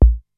VCF BASE 1 3.wav